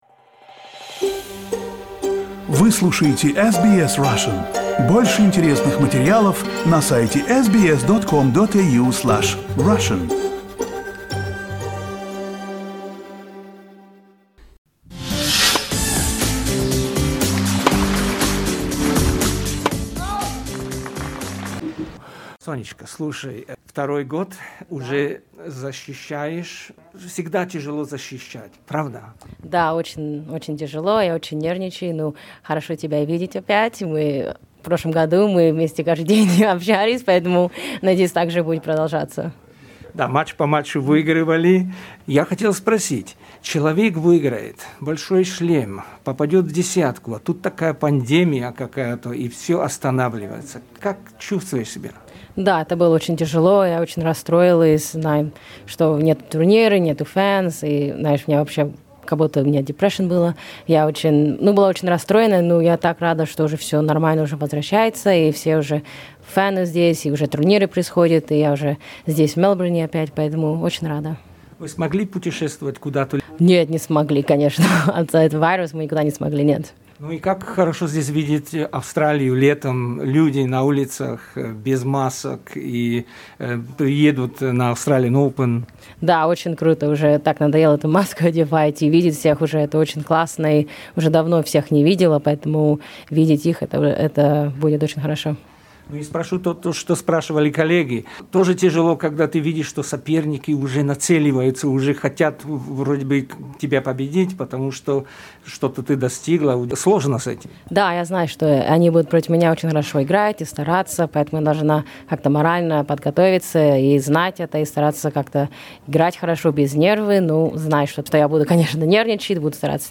Interview with Sofia Kenin at Australian Open for SBS Russian.